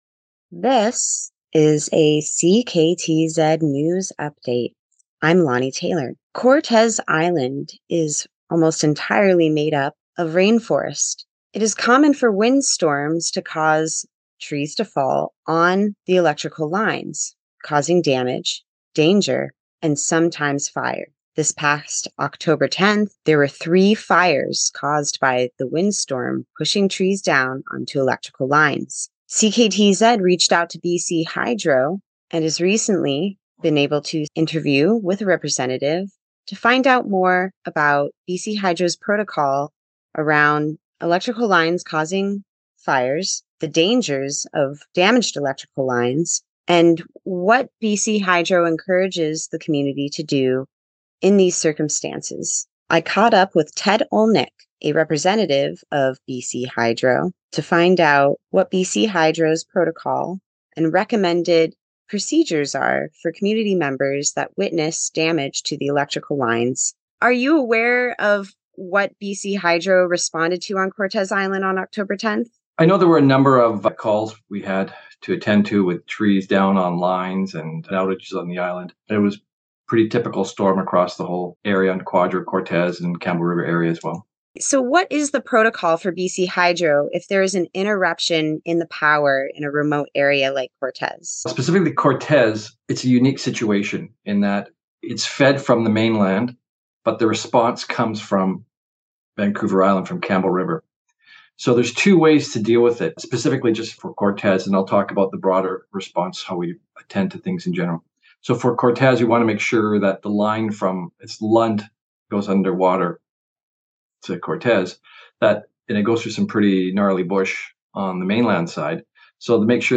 CKTZ-News-BC-Hydro-Windstorm-Tree-Safety.mp3